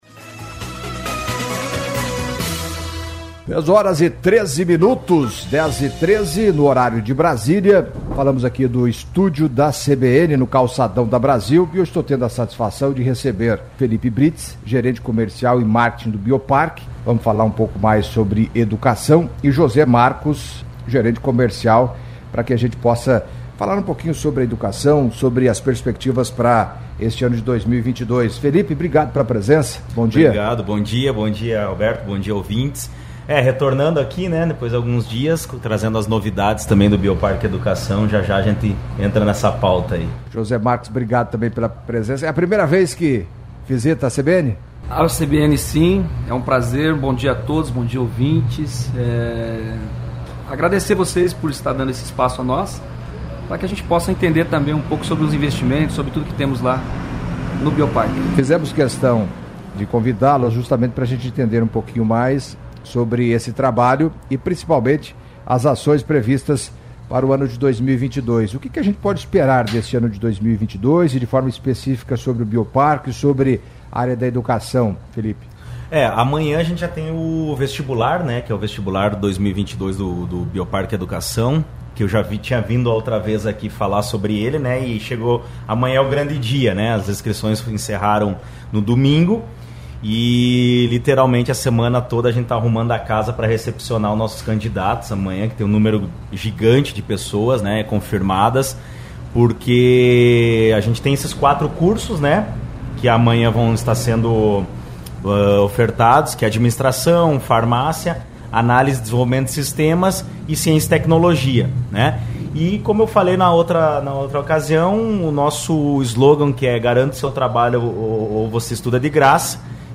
Em entrevista à CBN Cascavel nesta sexta-feria